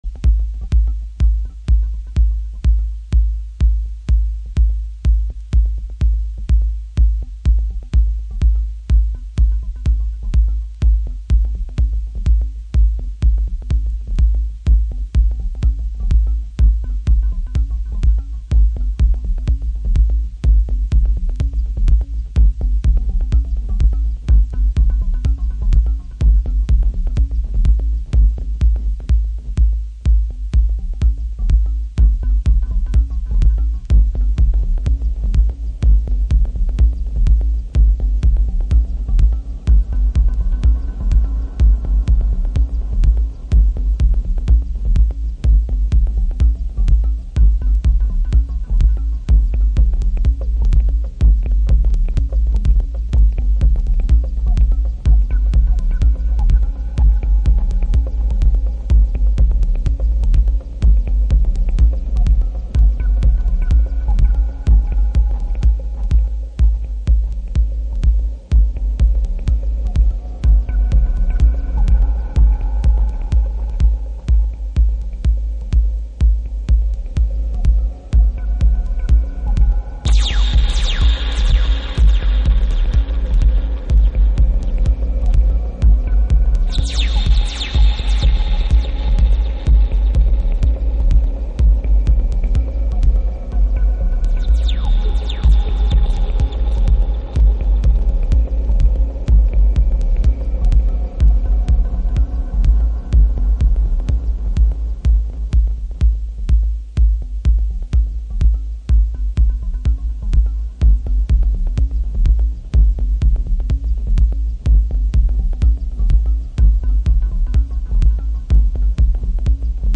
House / Techno
ダークサイドなエレクトロ。